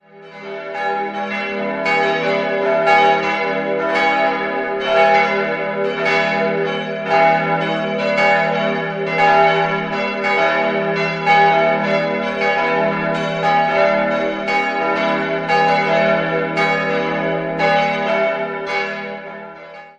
Der heutige Inneneindruck geht auf Umgestaltungen im Jahr 1972 zurück. 4-stimmiges Salve-Regina-Geläute: e'-gis'-h'-cis'' Alle Glocken wurden bereits 1946 von der Gießerei Petit&Edelbrock in Gescher hergestellt.